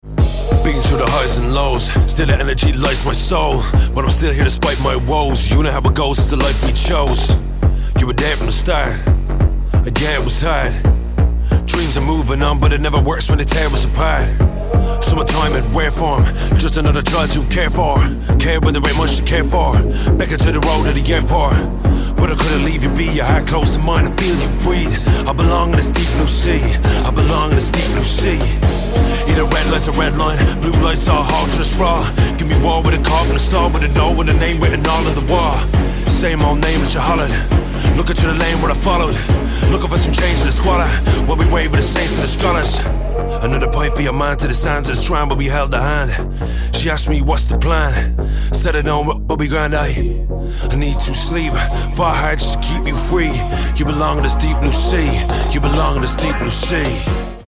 Male
20s/30s, 30s/40s
Irish Dublin City